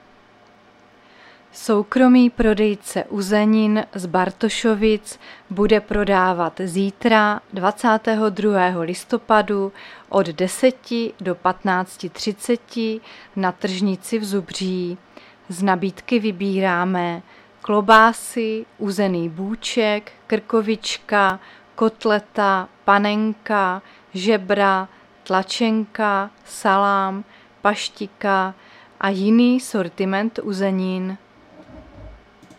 Záznam hlášení místního rozhlasu 21.11.2023